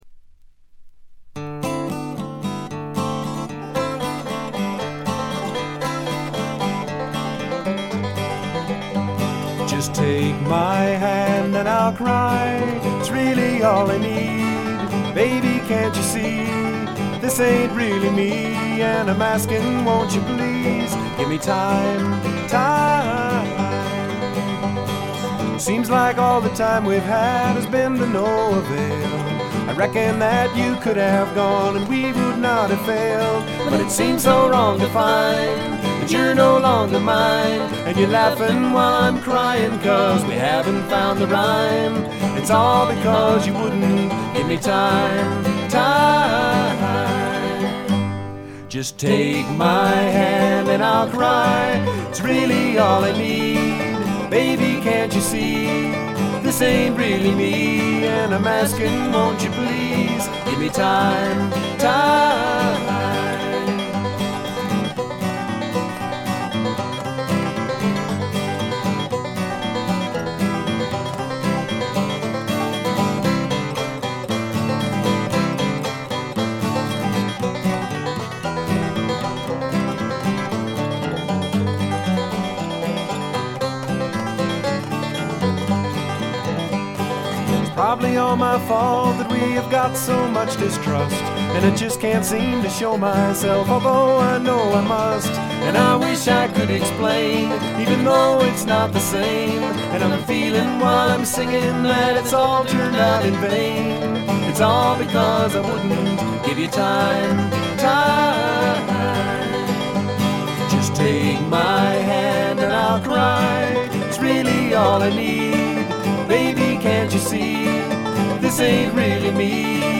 微細なチリプチわずか。
全体に静謐で、ジャケットのようにほの暗いモノクロームな世界。
試聴曲は現品からの取り込み音源です。